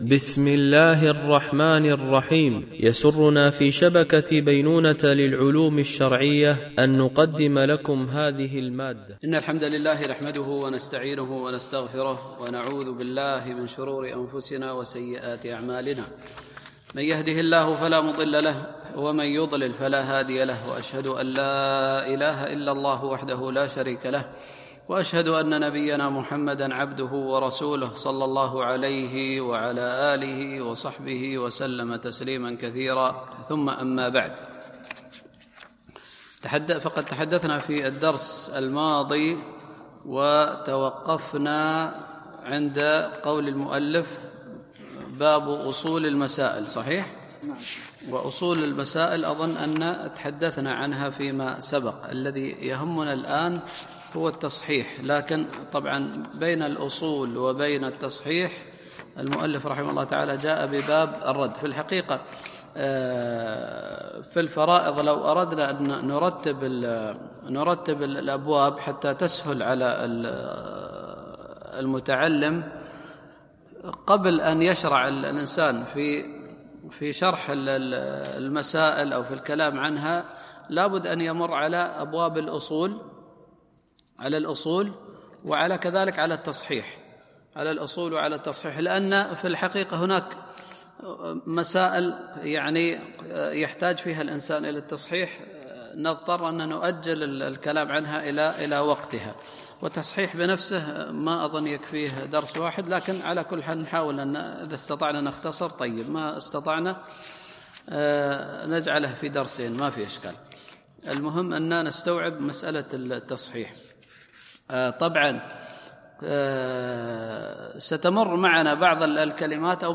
MP3 Mono 44kHz 64Kbps (VBR)